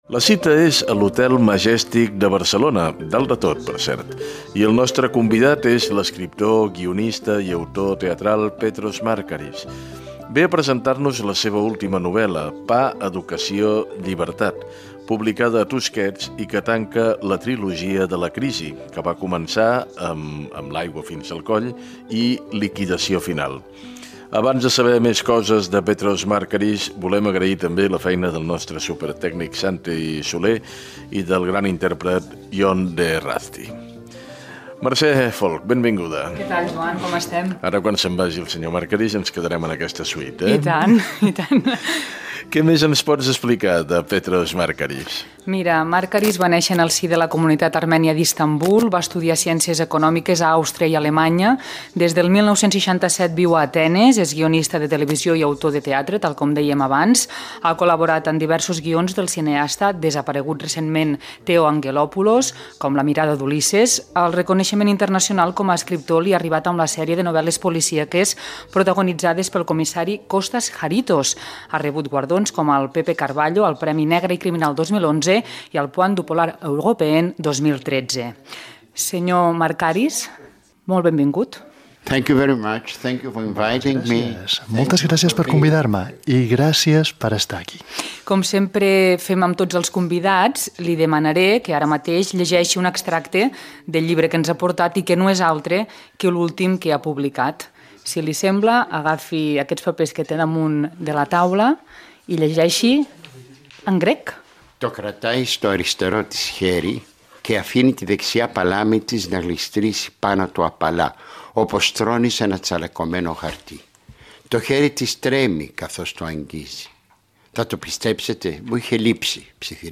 Entrevista a l'escriptor Petros Markaris que presenta el llibre "Pa, educació, llibertat"
Informatiu